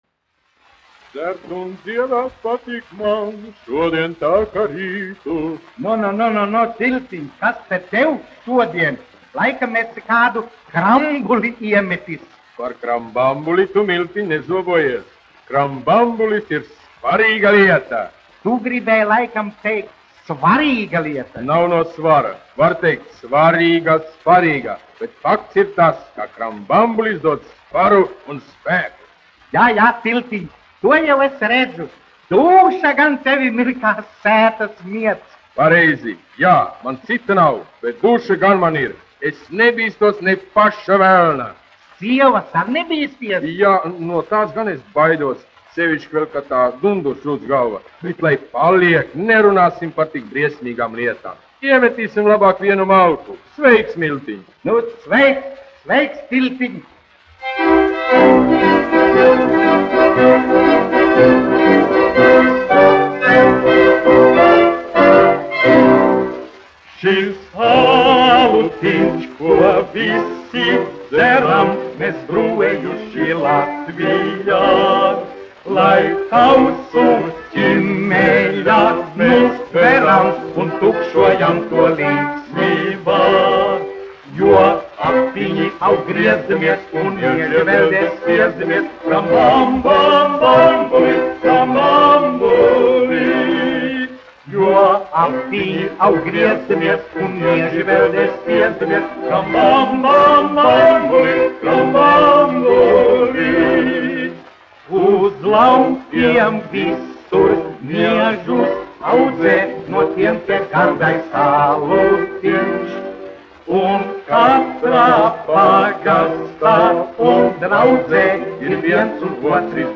1 skpl. : analogs, 78 apgr/min, mono ; 25 cm
Humoristiskās dziesmas
Skaņuplate
Latvijas vēsturiskie šellaka skaņuplašu ieraksti (Kolekcija)